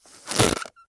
Звуки праздничной дудки
9. Подул но звука нет не сработала